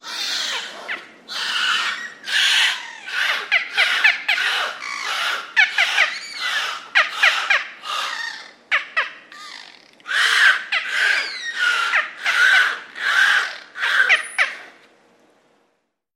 Белуги общаются между собой в дельфинарии